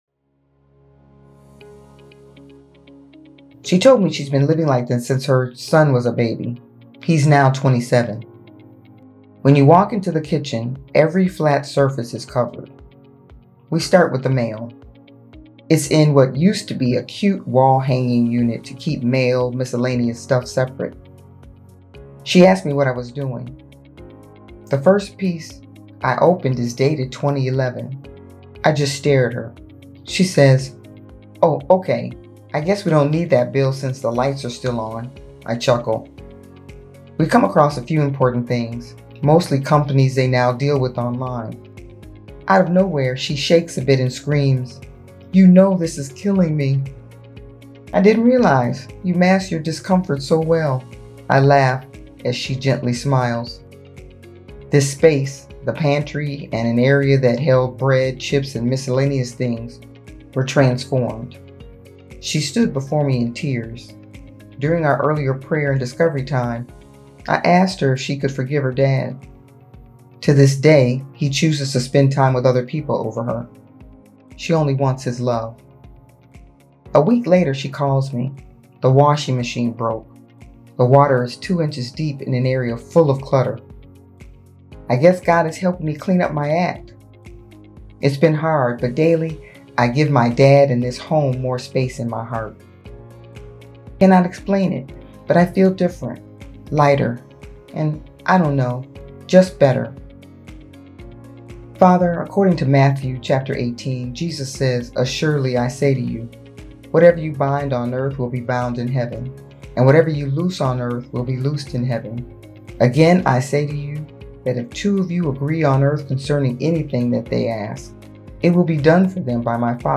She shares words of inspiration, encouragement, and prayer.